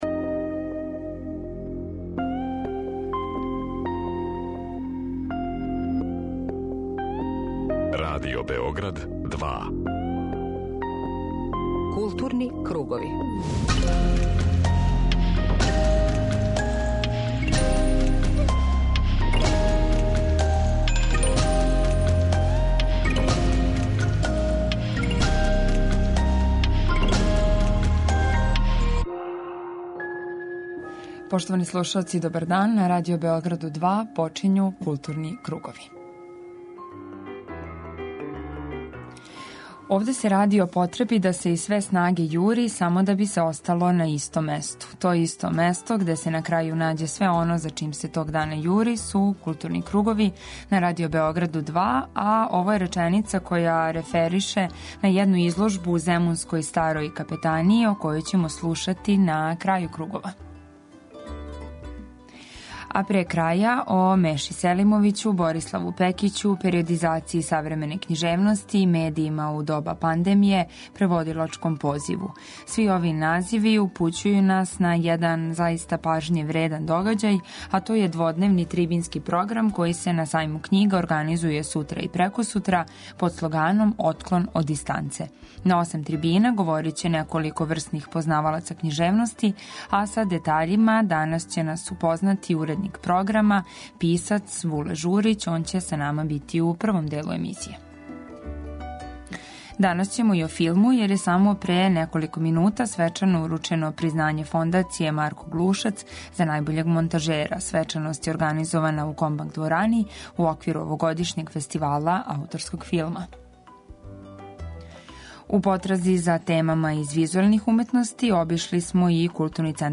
Чућемо и извештај са доделе награде „Марко Глушац" за најперспективнијег млађег монтажера дугих и кратких, играних и документарних форми. Пажњу посвећујемо и децембарским изложбама у Културном центру Београда.